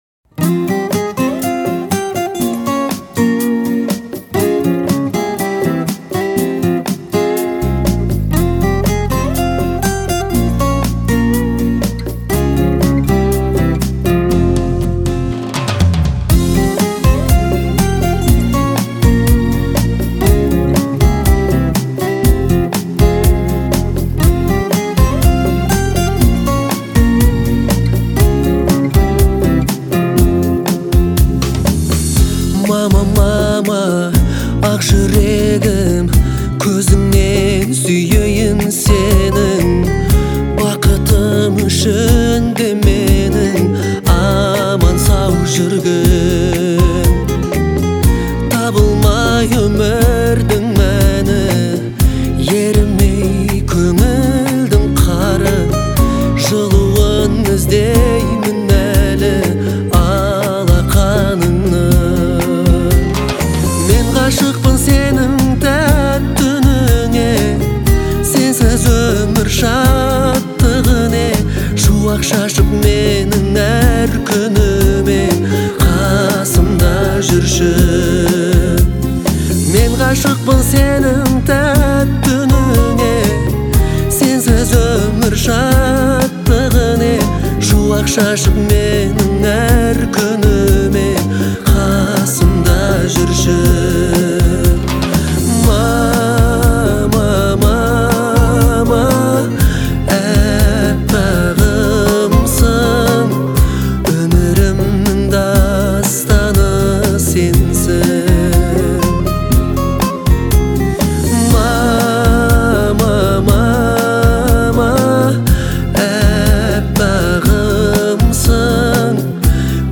Звучание отличается мелодичностью и простотой